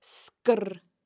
In Gaelic, sgr has two distinct sounds, depending on whether it occurs next to a broad vowel (a, o, u) or a slender vowel (e, i).